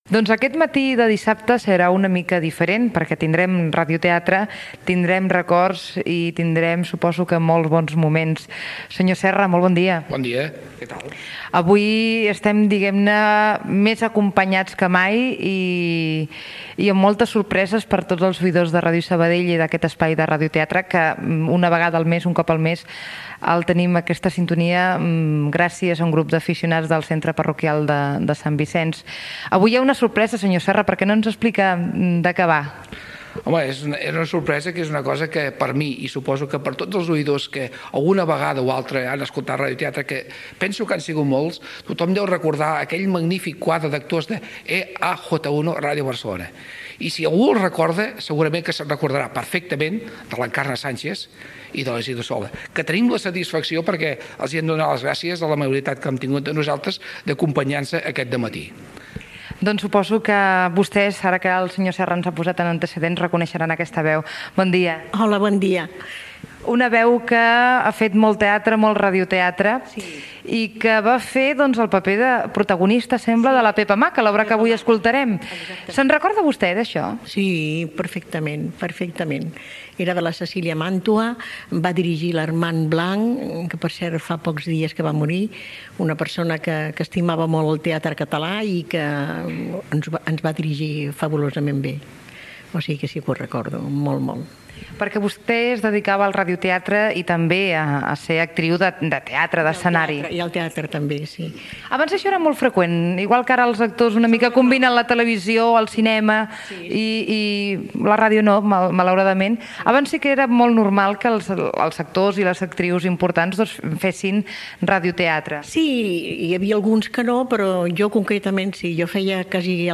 Gènere radiofònic